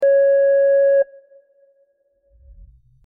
Interface Beep 03
Interface_beep_03.mp3